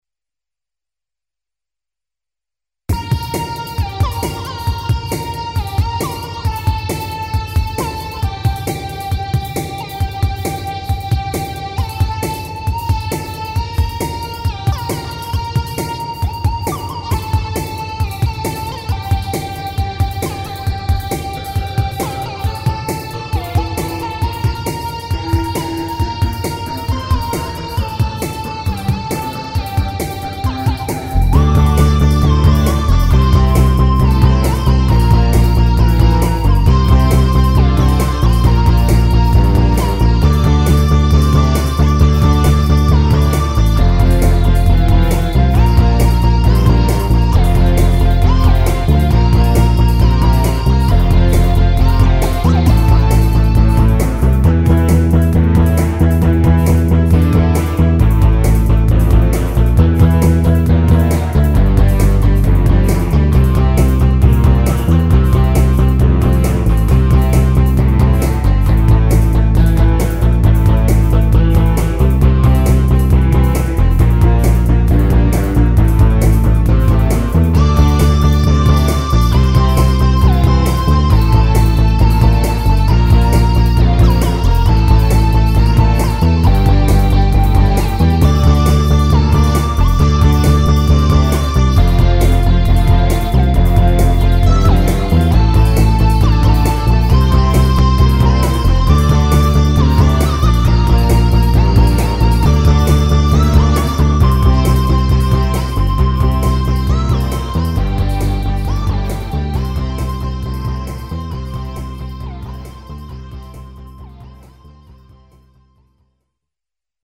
Hopalong (1.50) Mi (E) -  135
Plugins :  Font12! , Evm Bass Line , MDA EPiano , Synth1 ,
Drum loop : Arythm
Mode : Lydien